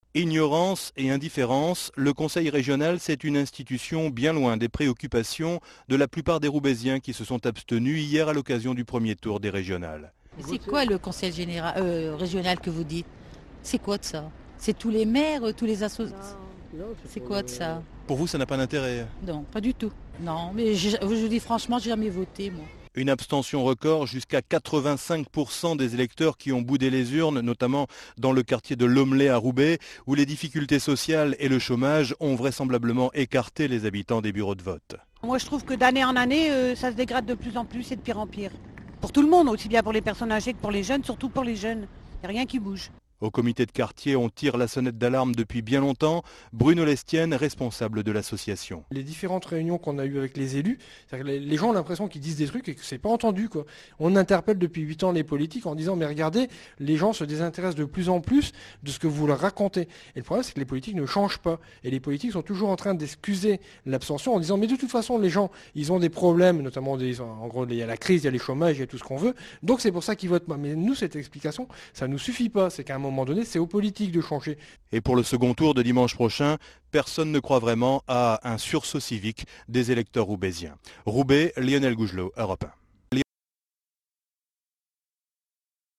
Europe 1 dresse un portrait de l’abstention à Roubaix, avec des interviews d’habitants du quartier de l’Hommelet.